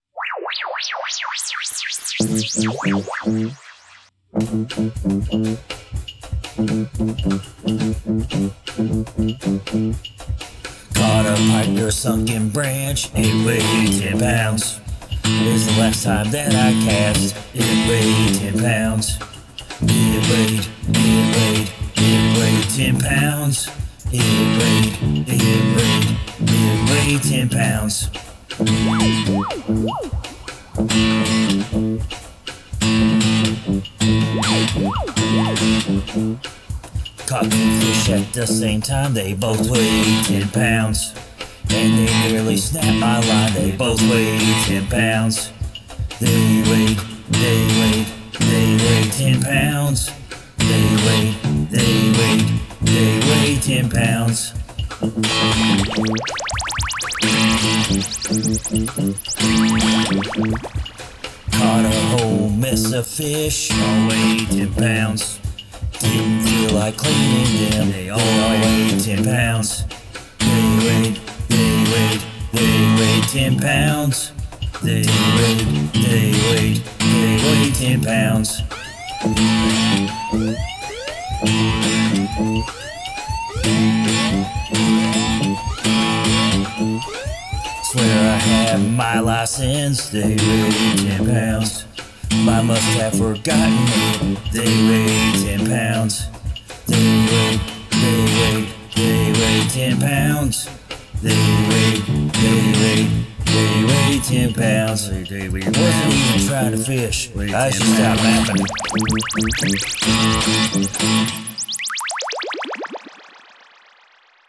Trippy music!
trippy and glitchy. great lyrics.
Attention-catching intro electronic squeals.
It was an experimental piece for sure.